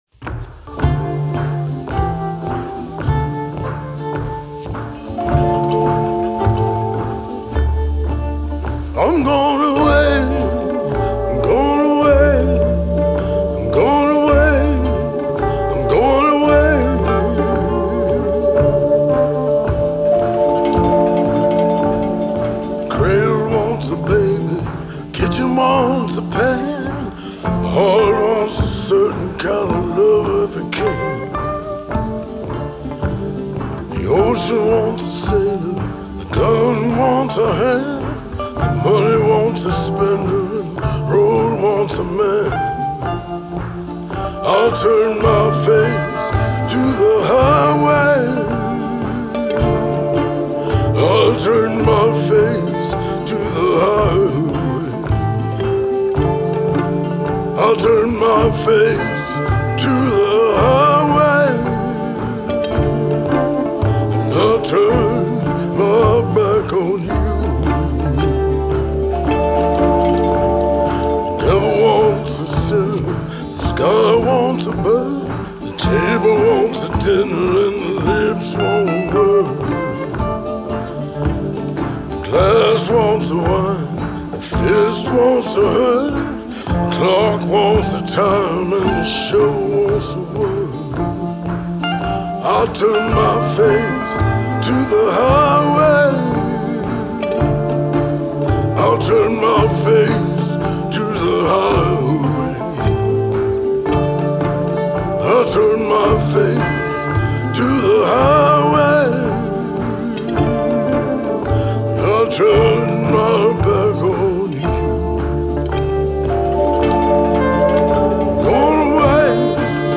as a weird, Cookie-monster-esque singer.